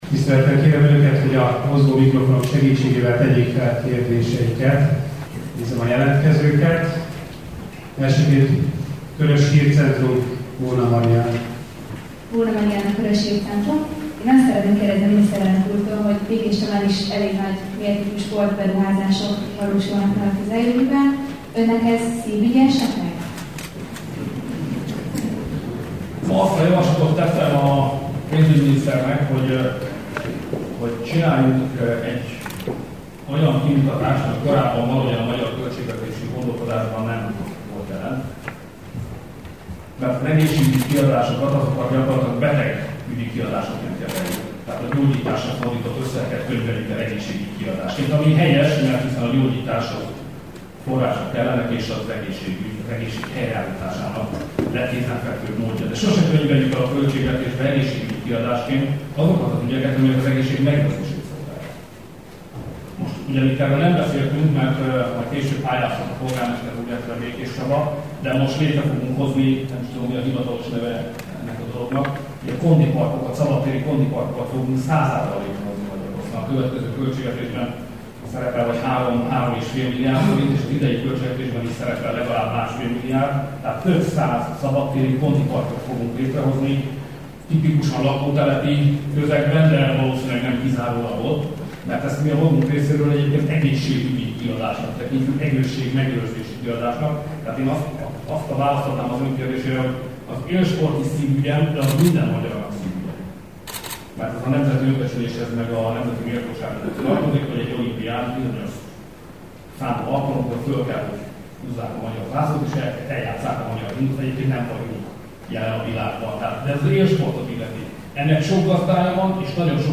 Az megállapodás aláírását követő sajtótájékoztató hang- és videóanyagát feldolgozzuk és közzétesszük a Körös Hírcentrum hírportálon.